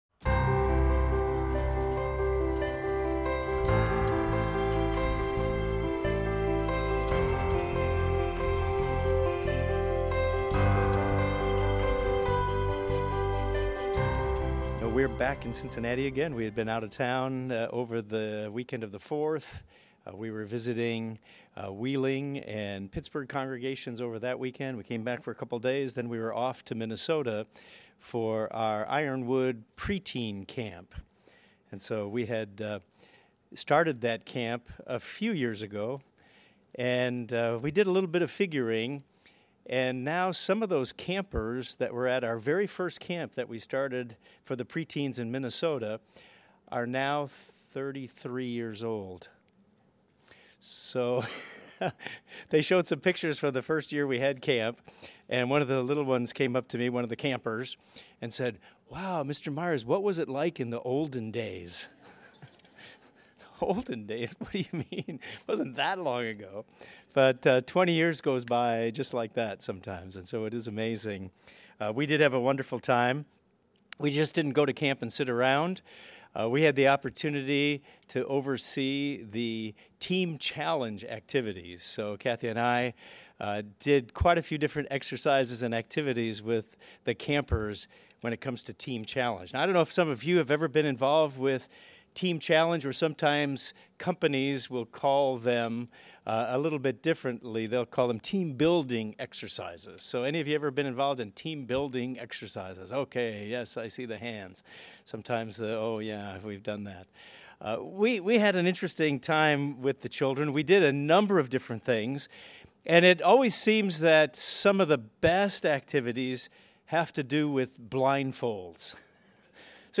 Yet, trust may be the most important factor in knowing God and His will for your life. This sermon demonstrates that you can you grow in developing a deeper relationship of trust with God and walk in His way.